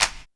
Index of /90_sSampleCDs/Best Service Dance Mega Drums/CLAPS HIP 3B